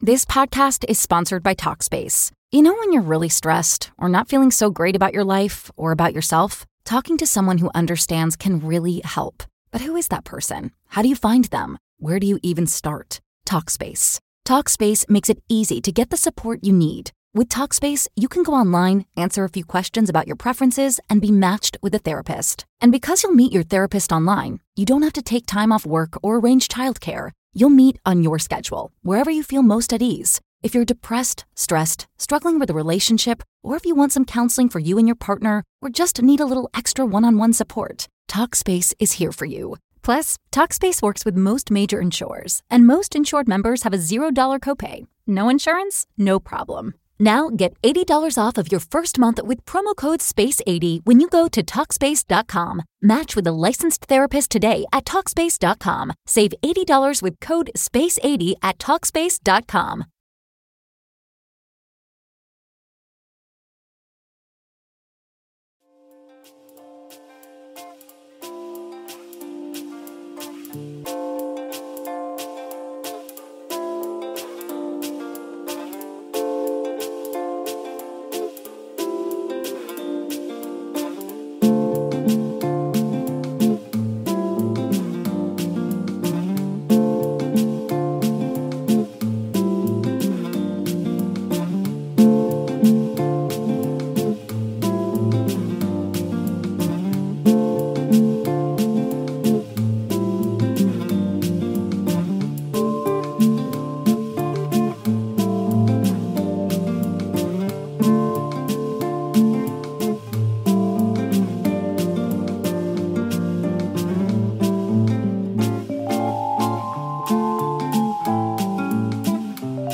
Live Q&A - 75 - Ask Me Anything!
Welcome to the live Q&A, where it is never a dull moment!